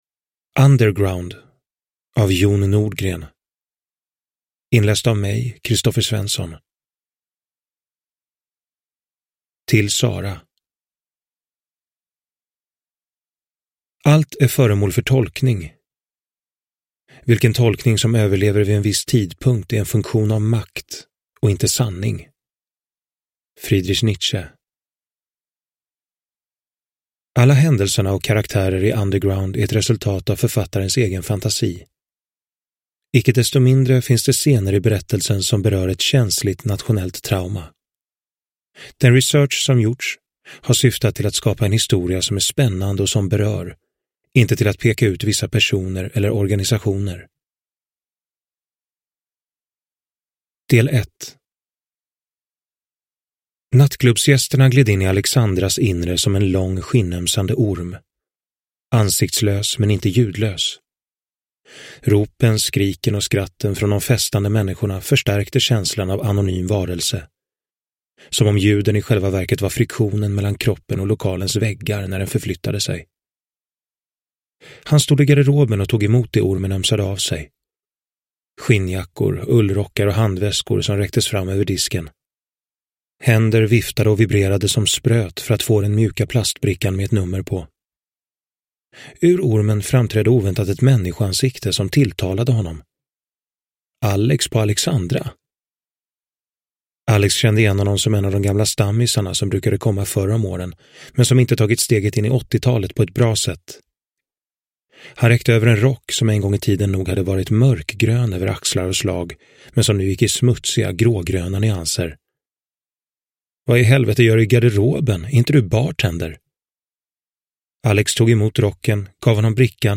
Underground – Ljudbok – Laddas ner